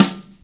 snare19.mp3